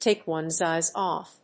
アクセントtàke one's éyes òff…